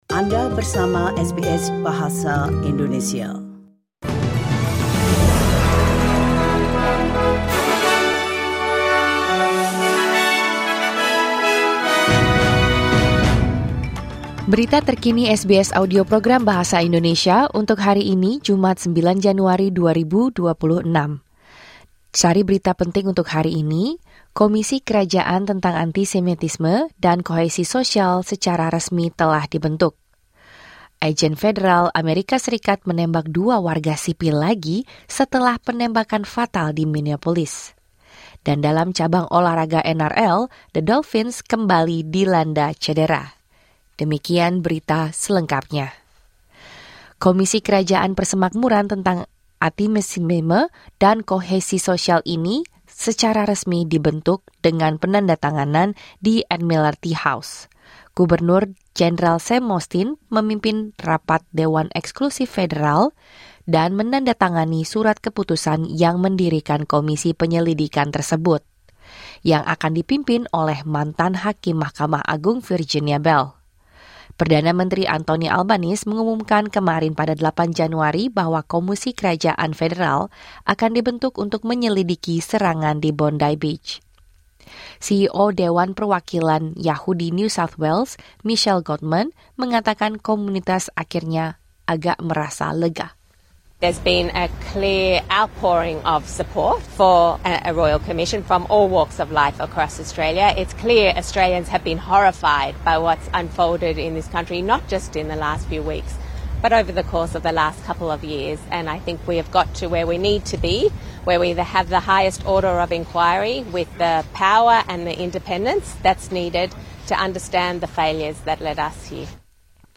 Berita Terkini SBS Audio Program Bahasa Indonesia - Jumat 9 Januari 2026